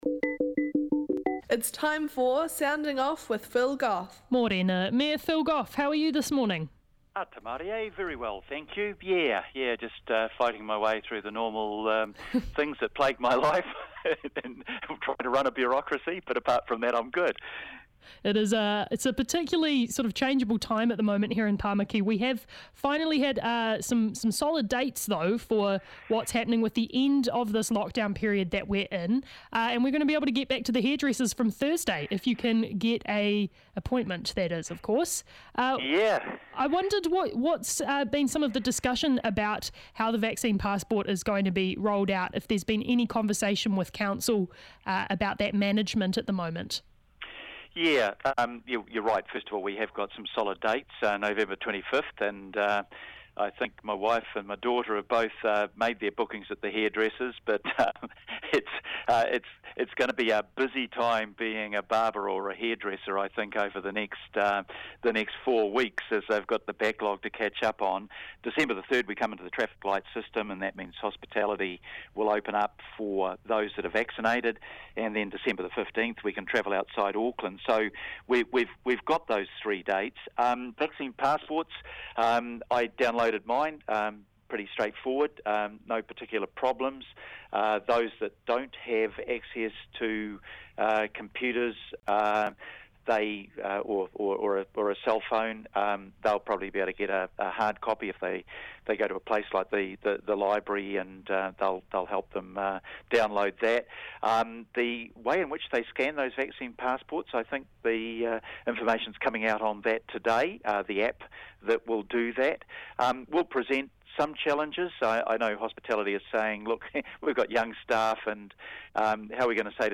Traffic lights, haircuts, Mr. Al Fresco, and housing with none other than Auckland's Mayor.